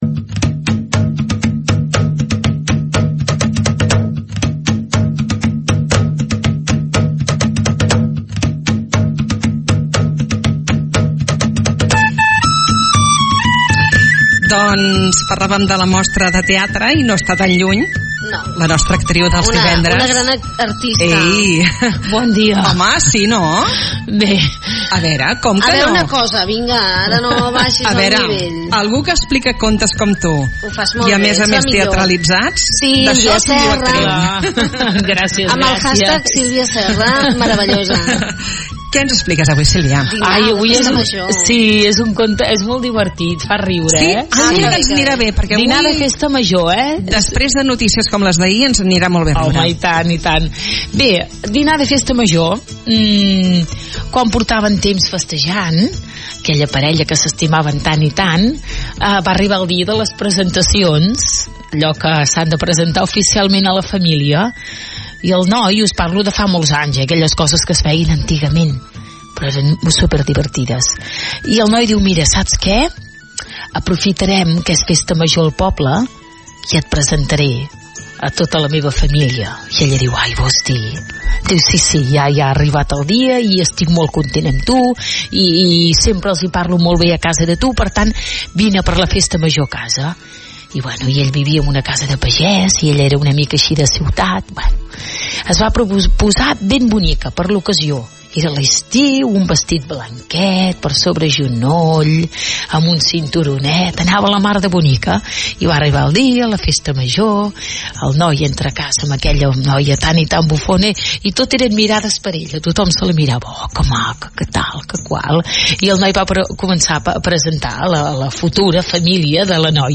Aquest és un conte peculiar que ens porta avui la nostra contacontes